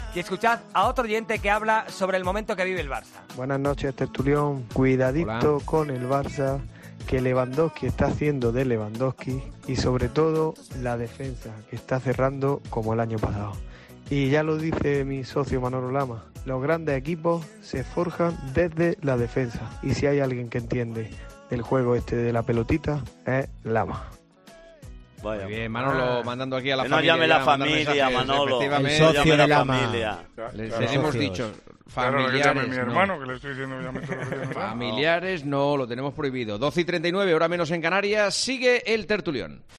Un aficionado destaca en El Tertulión la clave de la evolución del Barcelona: "Como el año pasado"